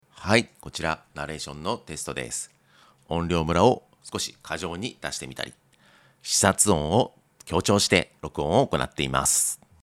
また、DynAssistはナレーションでも使用可能です。
DynAssistの効果が分かりやすくなるように、音量のばらつきやブレス音を意図的に過剰に録音しましたが、適用後はこれらも聴きやすくなっていることが感じられたかと思います。
Voice-DynAssist-Off.mp3